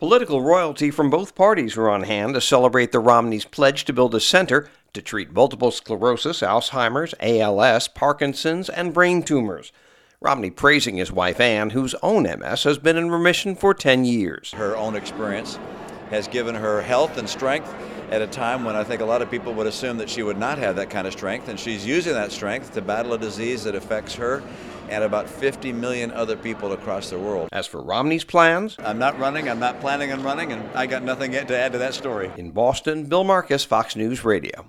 FORMER PRESIDENTIAL CANDIDATE AND MASSACHUSETTS GOVERNOR MITT ROMNEY AT THE OPENING GALA TO CELEBRATE HIS PLEDGE TO BUILD A NEUROLOGICAL CENTER EXPLAINS WHY THIS CENTER WILL BE UNIQUE.